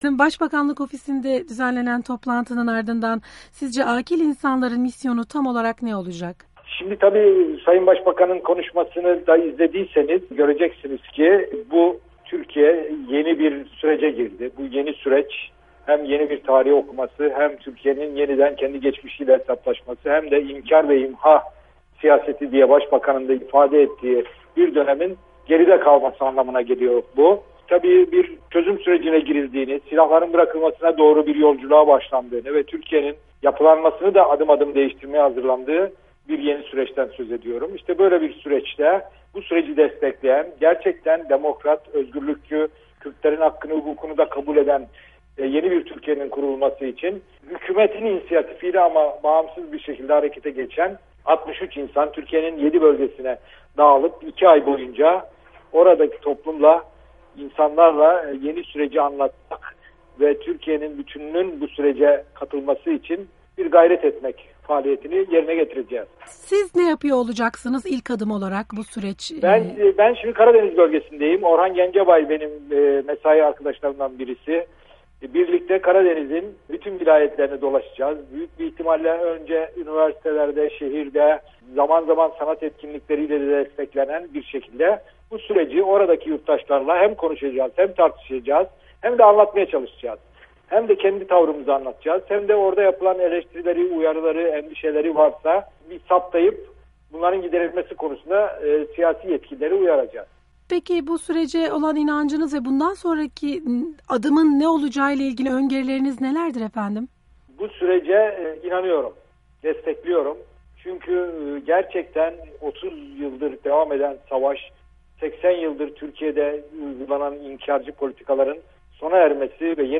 Oral Çalışlar ile Söyleşi